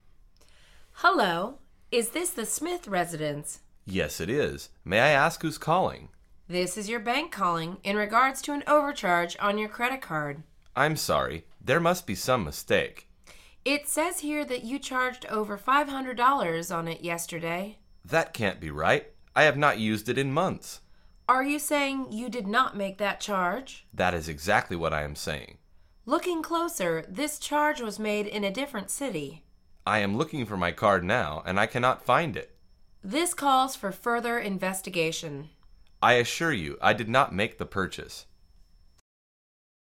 Dưới đây là một cuộc hội thoại giữa nhân viên ngân hàng và một khách hàng về vấn đề đã sử dụng quá $500 trong một ngày vì ở các nước phương Tây họ thường dùng thẻ tín dụng để mua sắm và ăn uống nhưng không quá nhiều so với số tiền quy định.